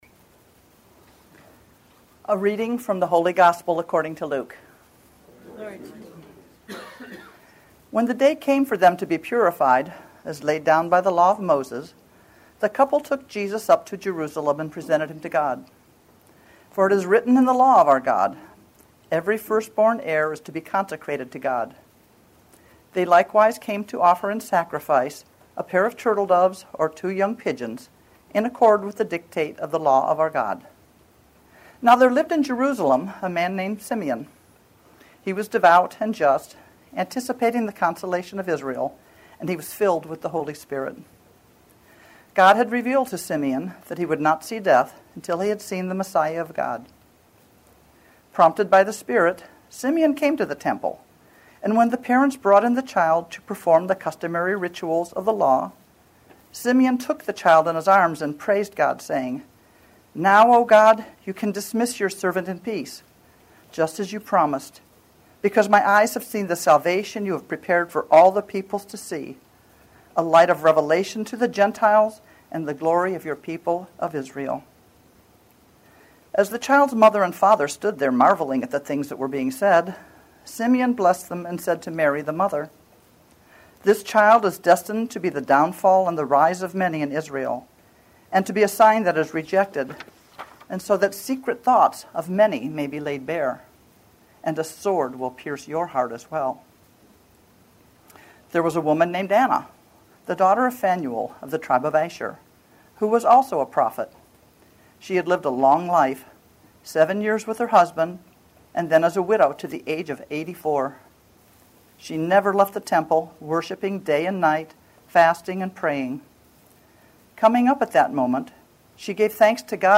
These homilies were offered during our 10a Sun weekly services. Each homily is preceded by the Gospel reading and followed by discussion. The discussion is not included in this podcast.